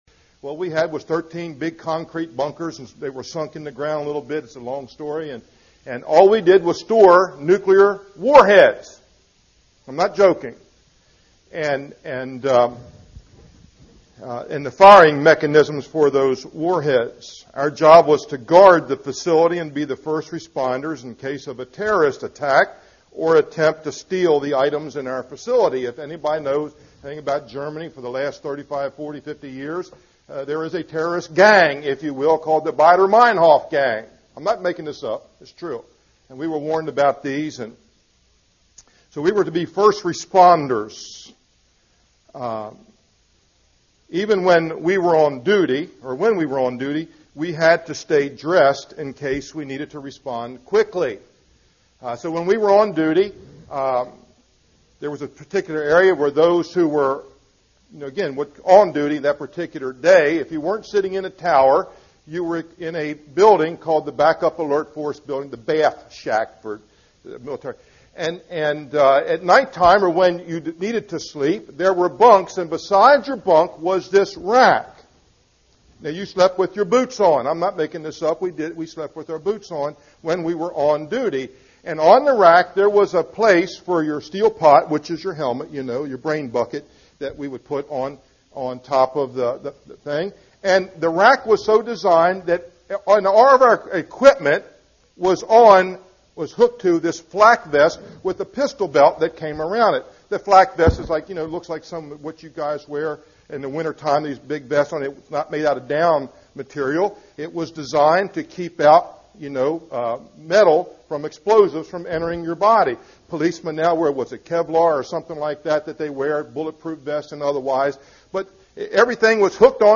preached on November 25th, 2007